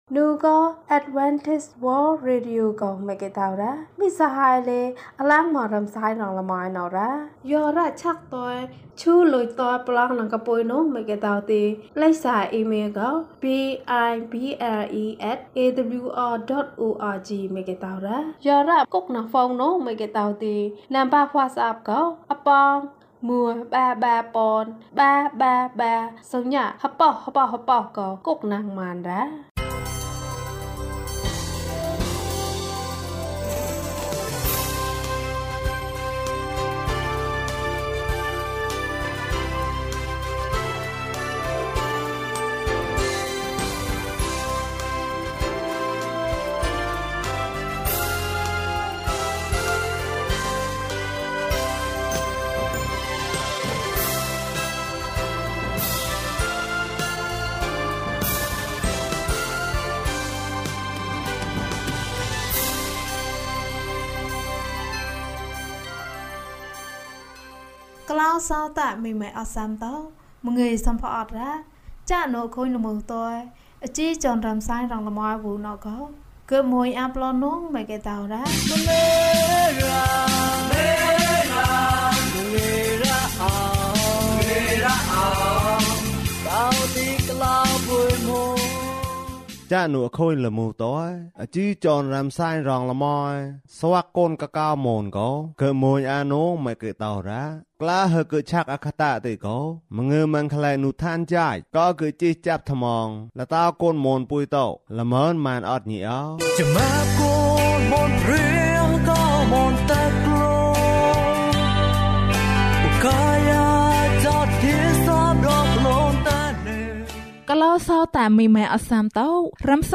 ဧဝံဂေလိတရား။၀၁ ကျန်းမာခြင်းအကြောင်းအရာ။ ဓမ္မသီချင်း။ တရားဒေသနာ။